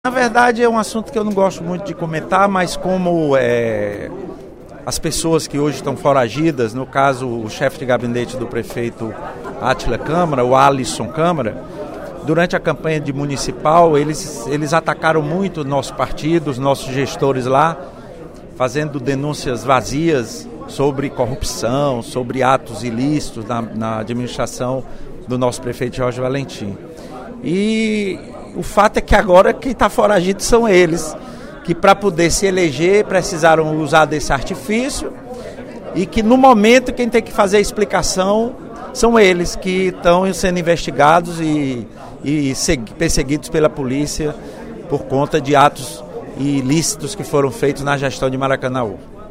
Durante o primeiro expediente da sessão plenária desta quinta-feira (20/03), o deputado Lula Morais (PCdoB) enfatizou a investigação do Ministério Público sobre crimes contra a administração pública de Maracanaú.